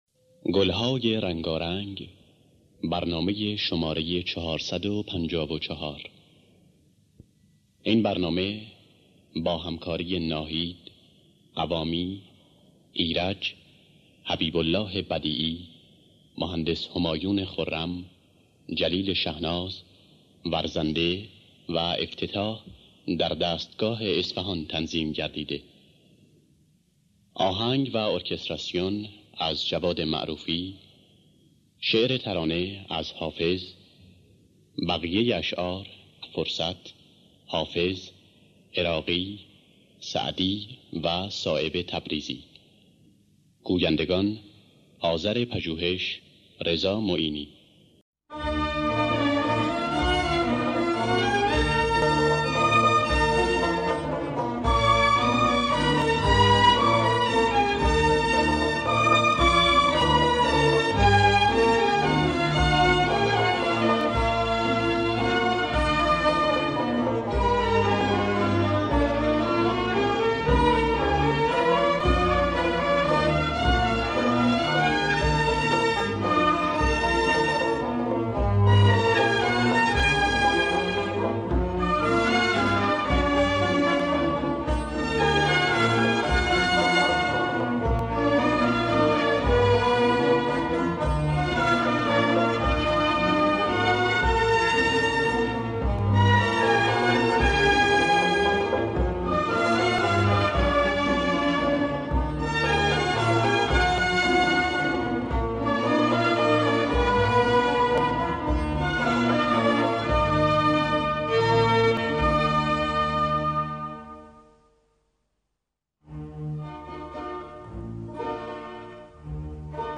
گلهای رنگارنگ ۴۵۴ - بیات اصفهان